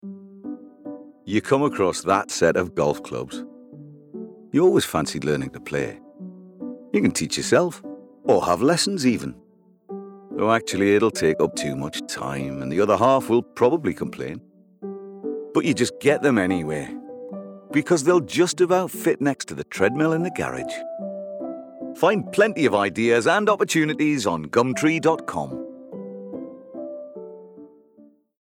Friendly, conversational with gravitas.
• Male
• Newcastle (Geordie)